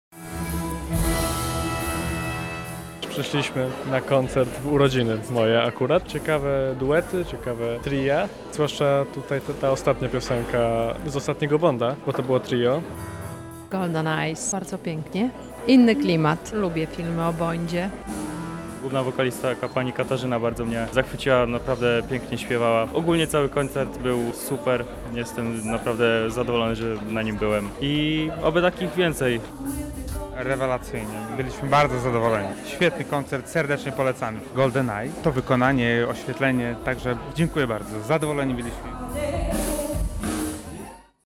Czy nowe aranżacje znanych utworów przypadły słuchaczom do gustu? Zapytaliśmy ich o to po koncercie.